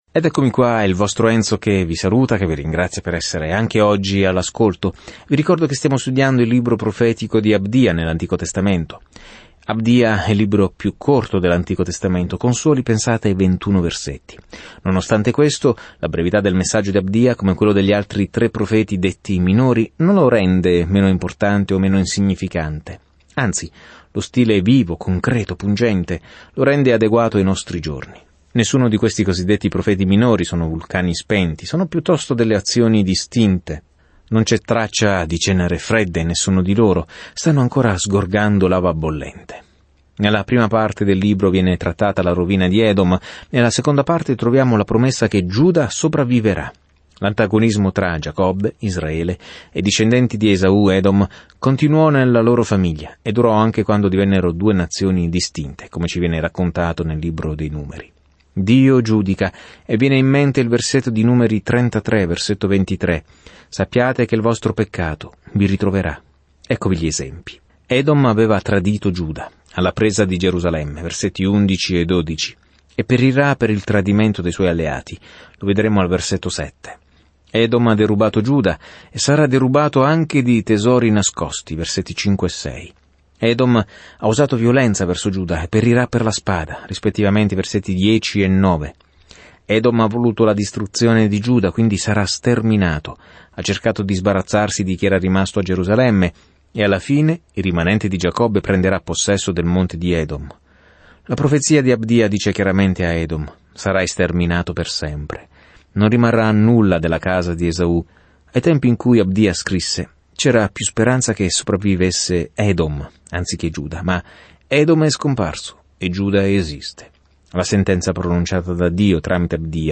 Scrittura Abdia 1:3-12 Giorno 2 Inizia questo Piano Giorno 4 Riguardo questo Piano Un cervo orgoglioso è più profondo e più oscuro di qualsiasi altro peccato perché dice che non abbiamo bisogno di Dio: è così che Abdia mette in guardia le nazioni confinanti con Israele. Viaggia ogni giorno attraverso Abdia mentre ascolti lo studio audio e leggi versetti selezionati della parola di Dio.